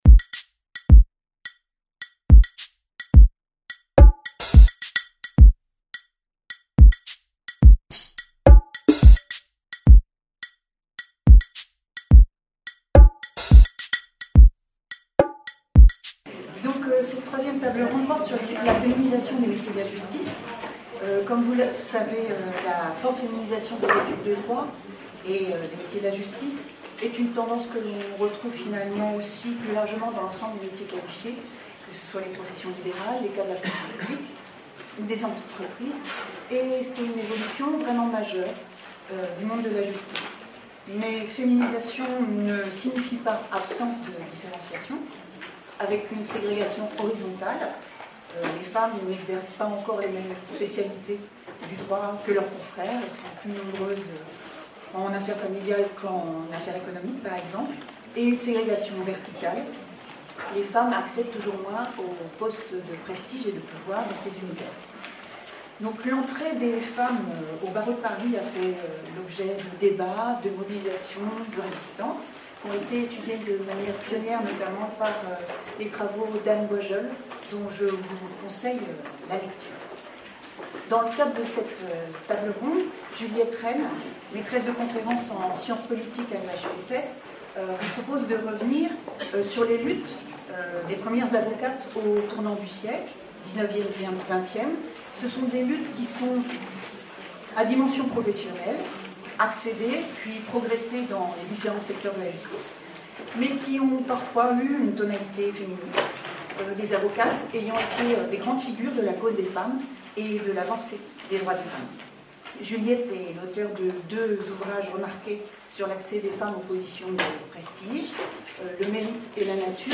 Table ronde 3: La féminisation des métiers de justice | Canal U